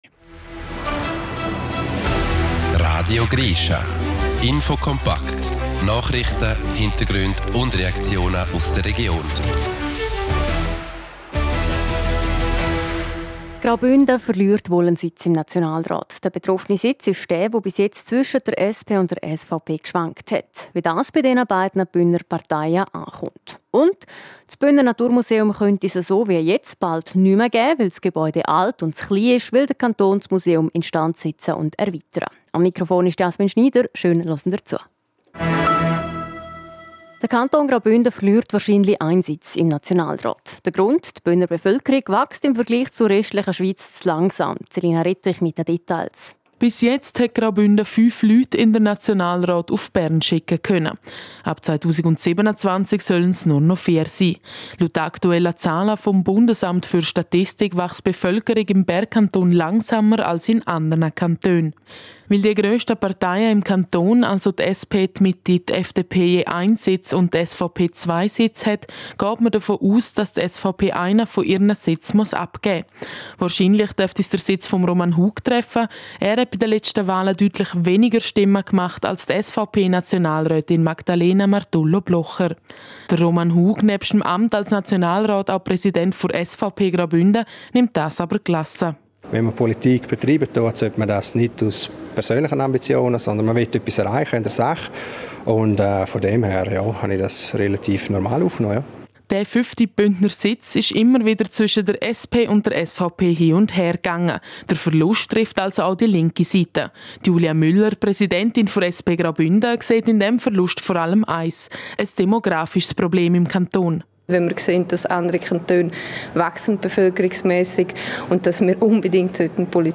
17:10 Uhr News Merken Like 0 Dislike 0 Teilen Facebook E-Mail WhatsApp Link